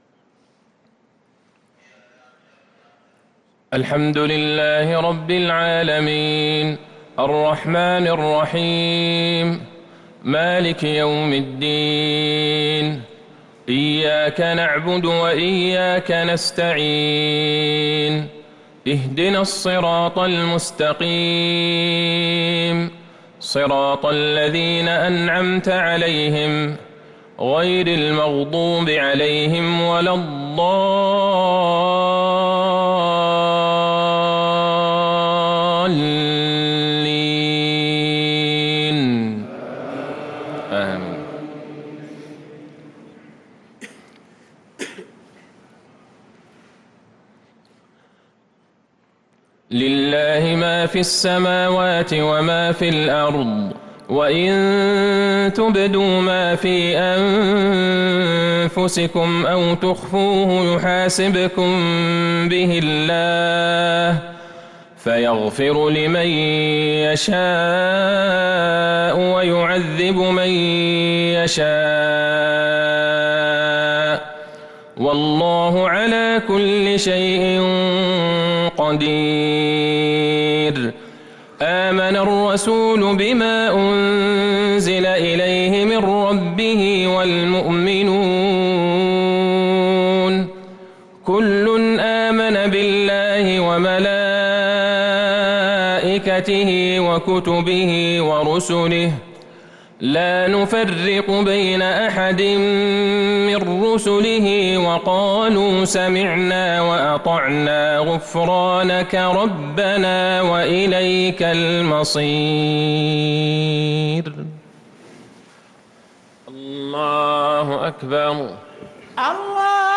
صلاة المغرب للقارئ عبدالله البعيجان 18 رمضان 1443 هـ
تِلَاوَات الْحَرَمَيْن .